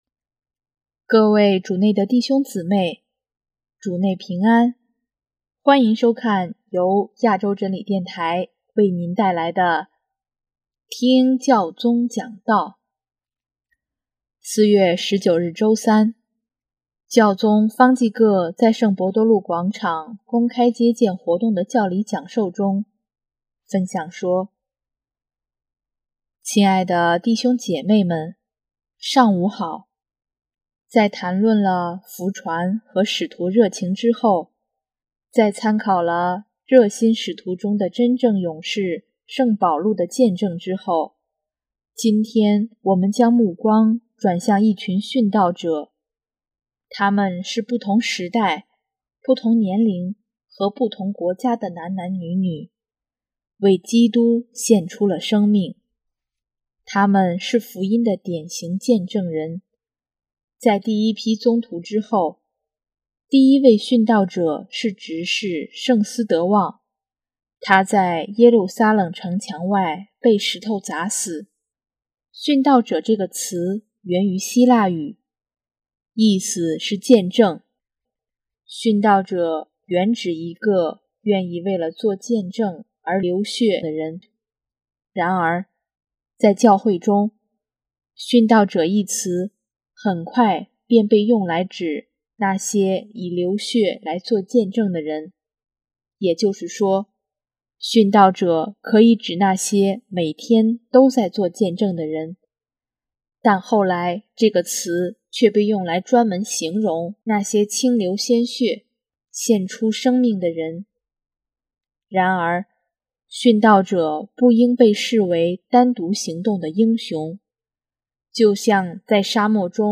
4月19日周三，教宗方济各在圣伯多禄广场公开接见活动的教理讲授中，分享说：